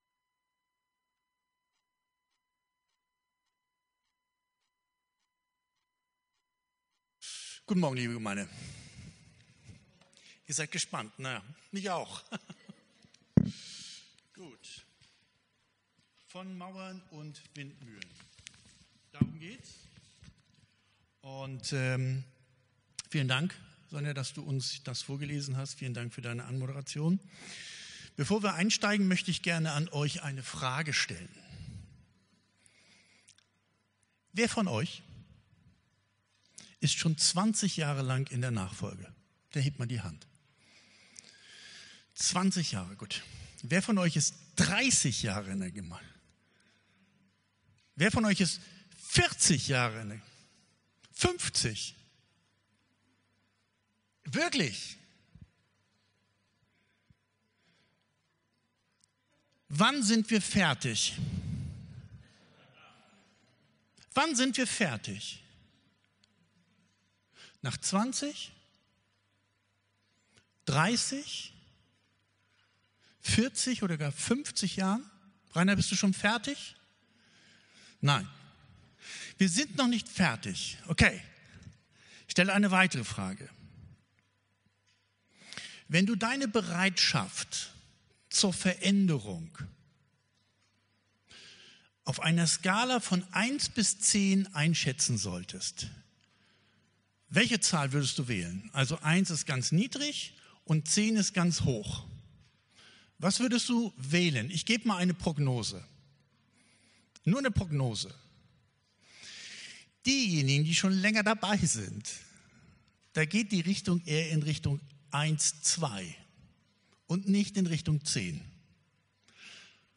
Predigt vom 27.07.2025